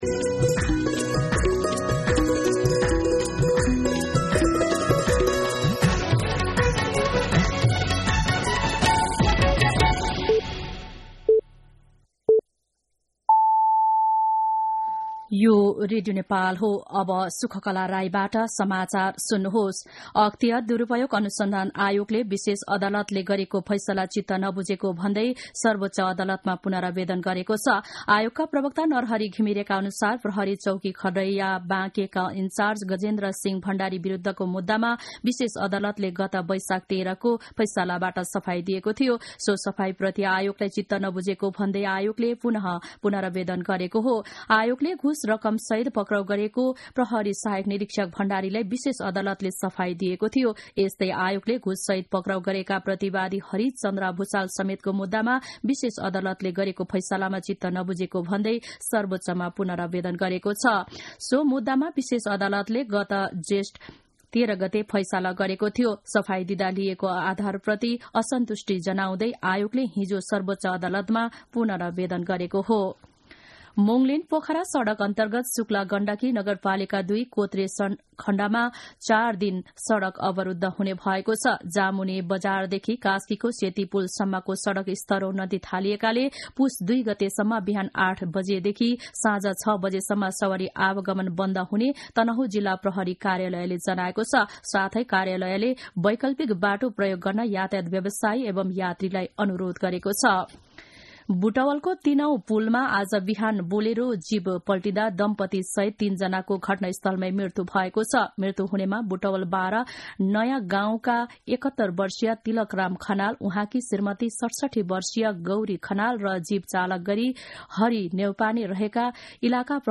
मध्यान्ह १२ बजेको नेपाली समाचार : ३० मंसिर , २०८१
12-mNepali-News.mp3